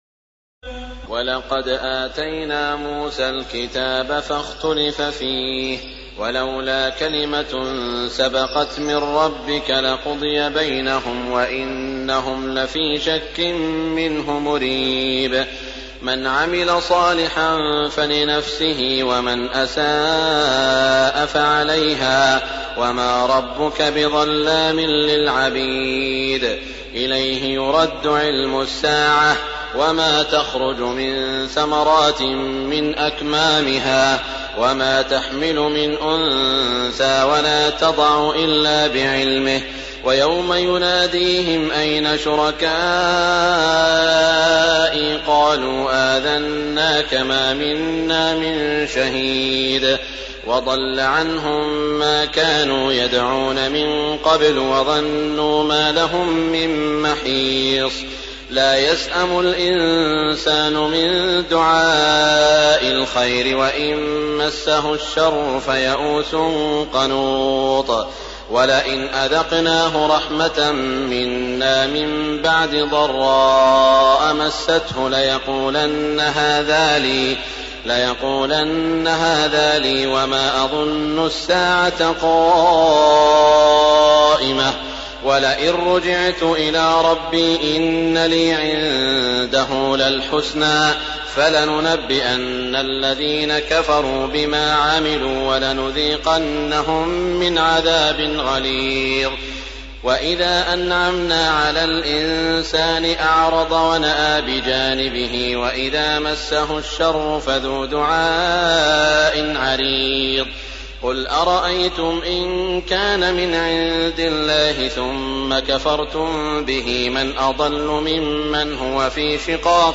تراويح ليلة 24 رمضان 1424هـ من سور فصلت (45-54) و الشورى و الزخرف (1-25) Taraweeh 24 st night Ramadan 1424H from Surah Fussilat and Ash-Shura and Az-Zukhruf > تراويح الحرم المكي عام 1424 🕋 > التراويح - تلاوات الحرمين